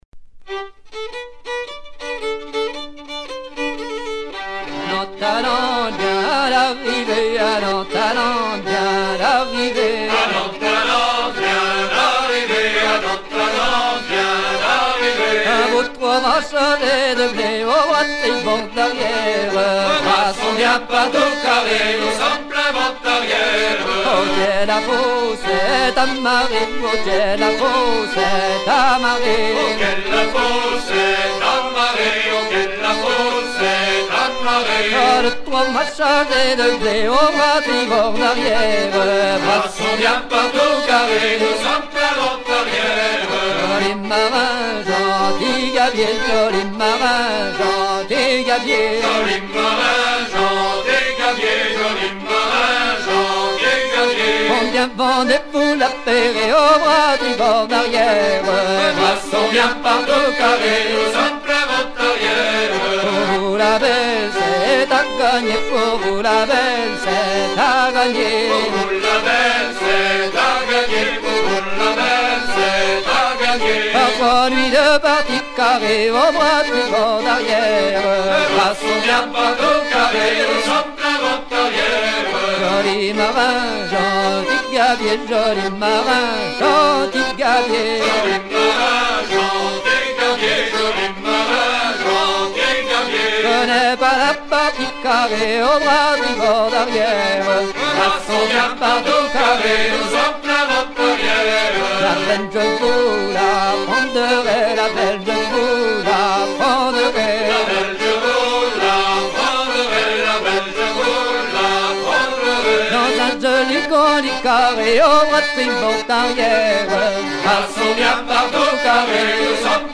gestuel : à virer au cabestan
Pièce musicale éditée